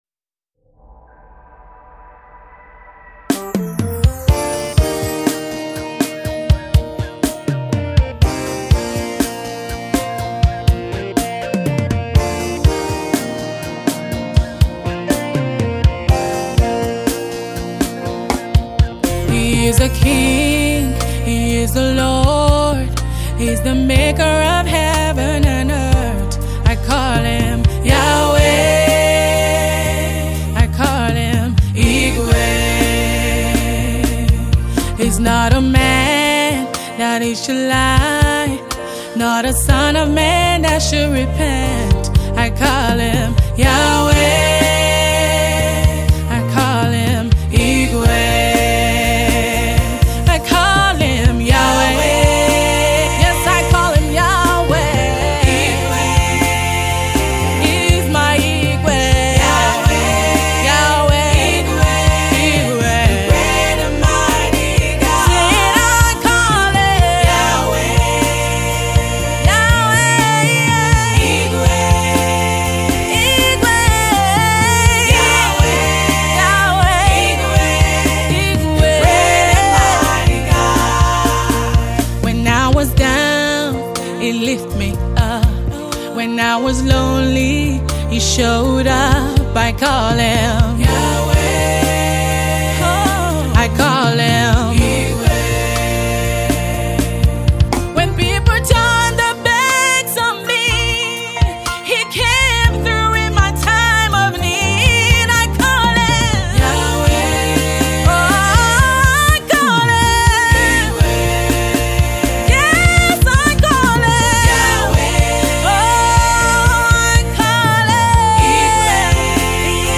new song of worship